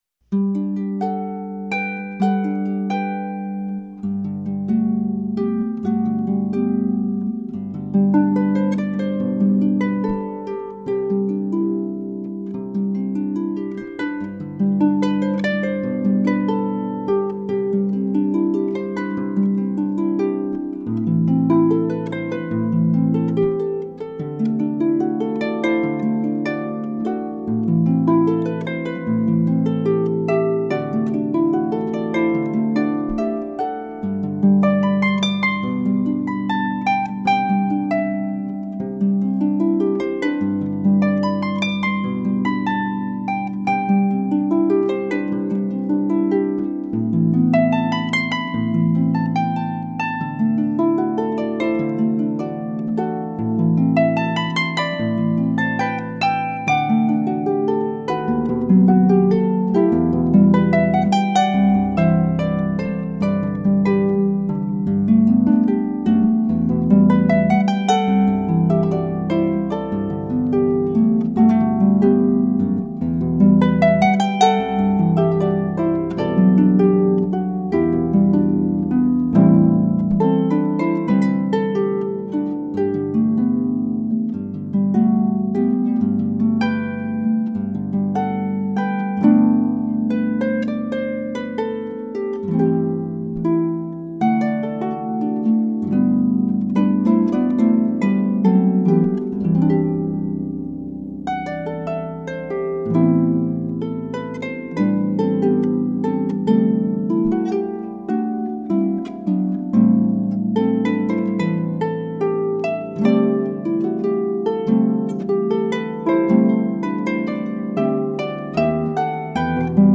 Harpist 2
harp2-1.mp3